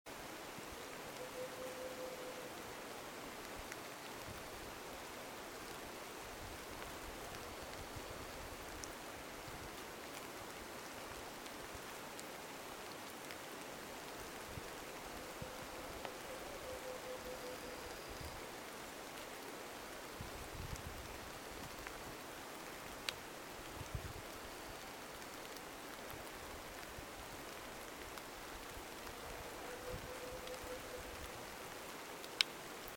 Variegated Antpitta (Grallaria varia)
Life Stage: Adult
Condition: Wild
Certainty: Recorded vocal